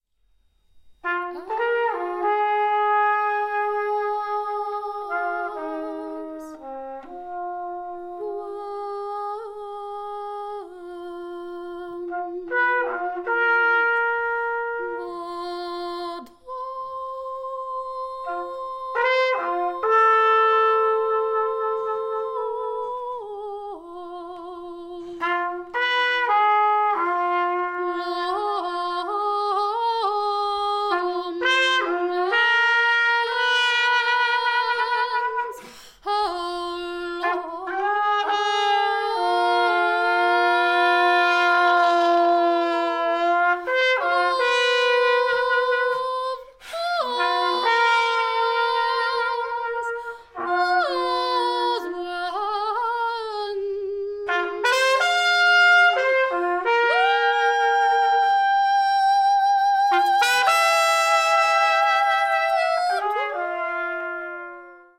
trumpet, horns, objects
voice, flutes, objects
Recorded at Carré Bleu, Poitiers, France